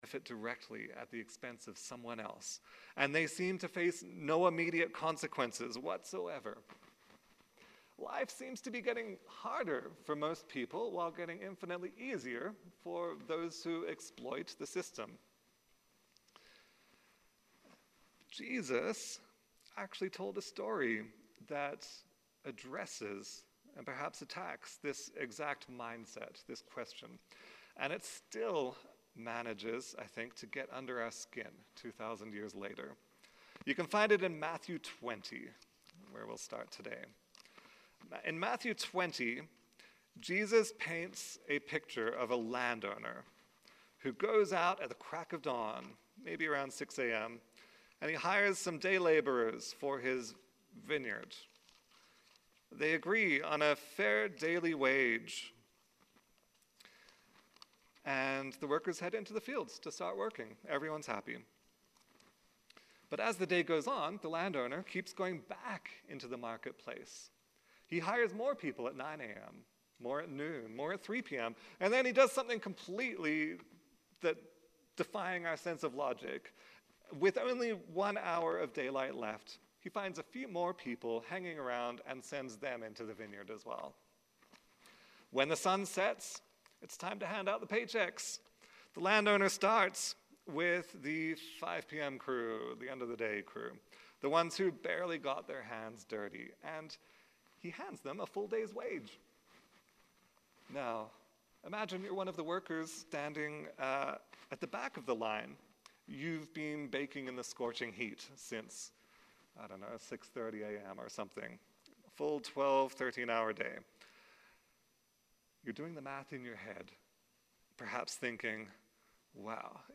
Sermons | Clairmont Community Church